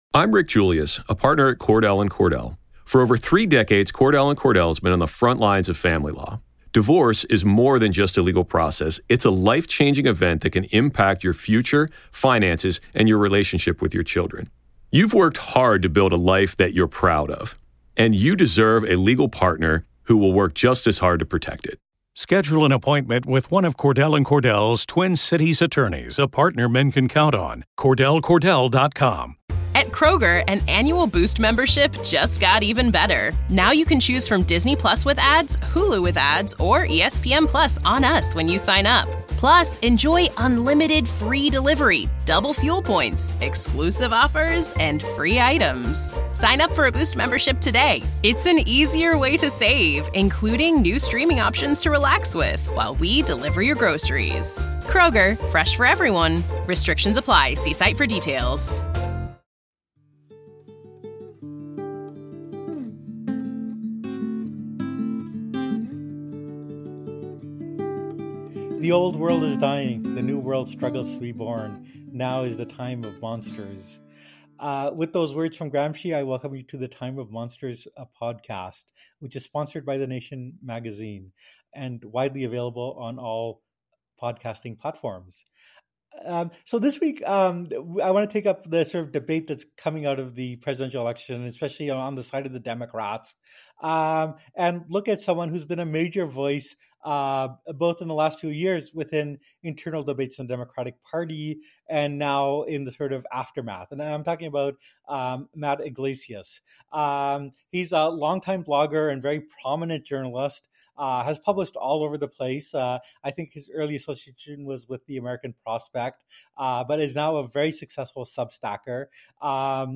Tonight we're diving into all that plus strange upright coyote-like creatures, messages from the beyond, sinister smiling entities, and much more. Season 18 Episode 23 of Monsters Among Us Podcast, true paranormal stories of ghosts, cryptids, UFOs and more, told by the witnesses themselves.